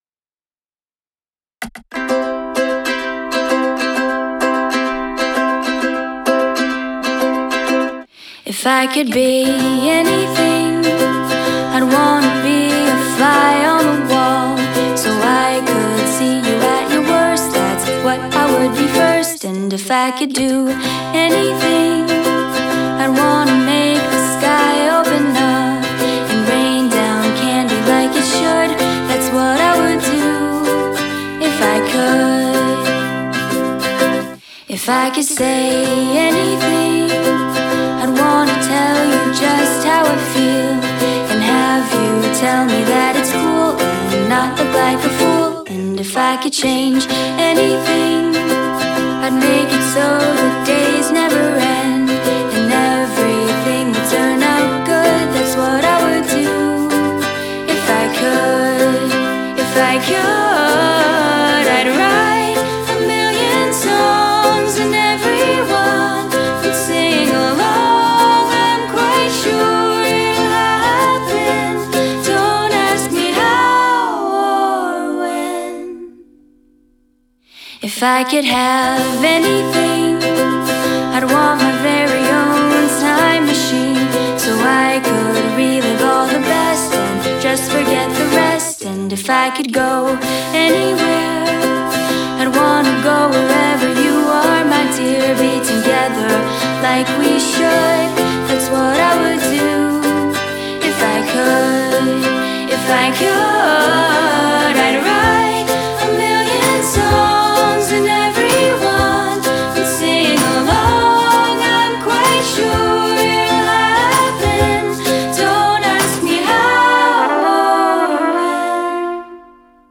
Armée de son ukulélé et entourée de quelques amis